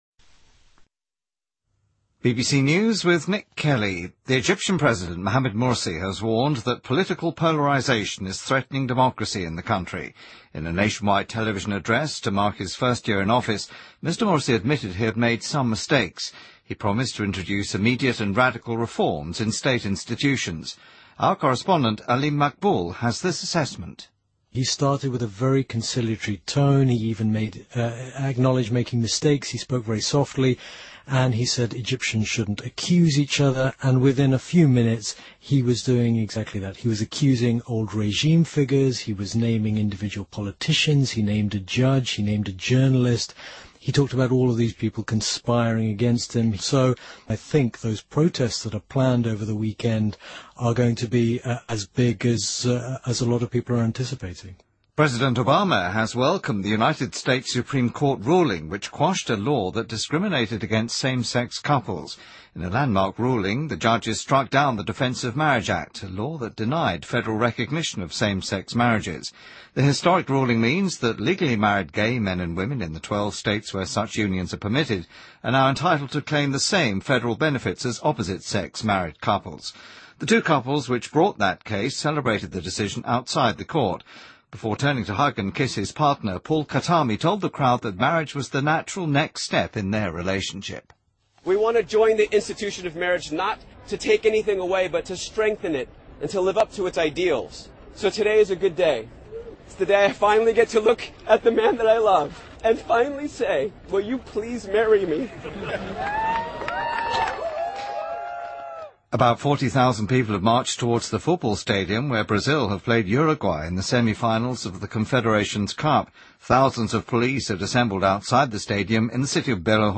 BBC news,美国警方控告橄榄球队员埃尔南德斯(Aaron Hernandez)谋杀罪